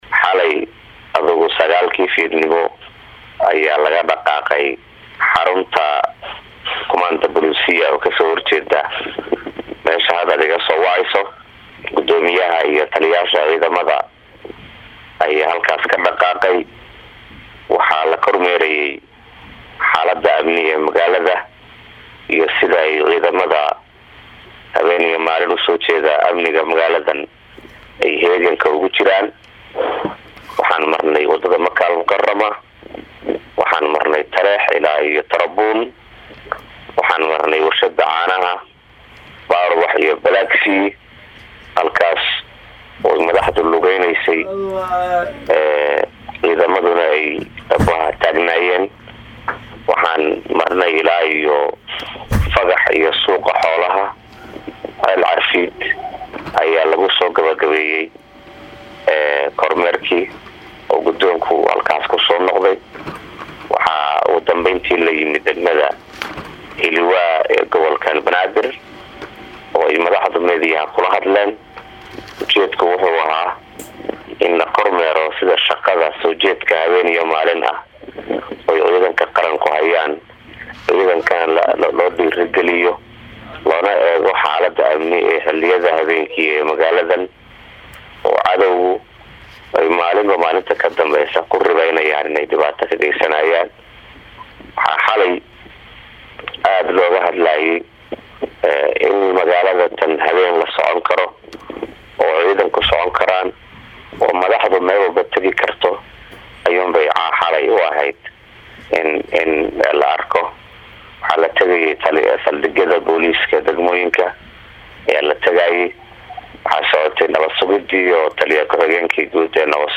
WARAYSI-AFHAYEEN-XALANE-1.mp3